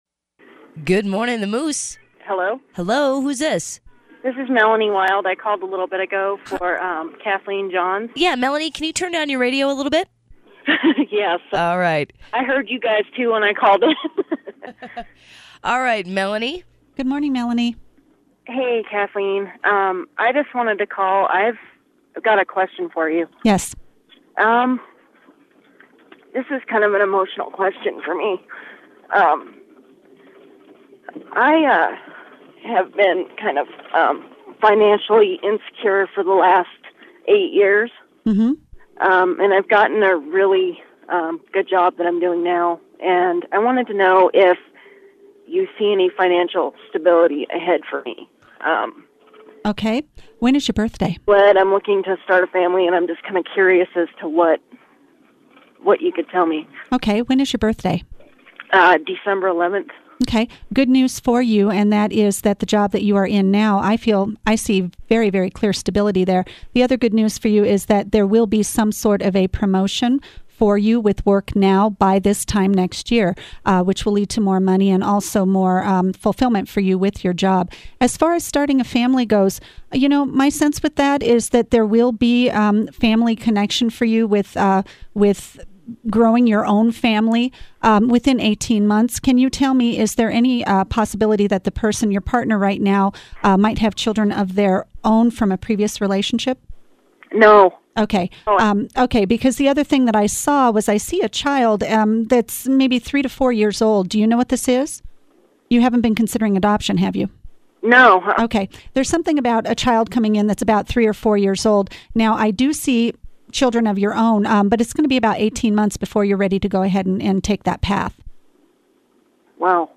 Live in the studio on KMMS 95.1 FM “The Moose” in Bozeman, Montana